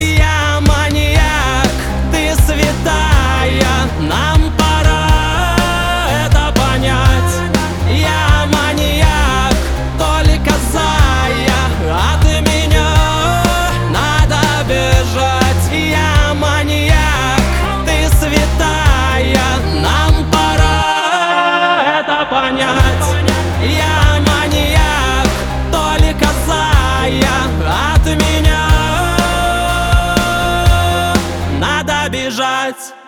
поп
барабаны , гитара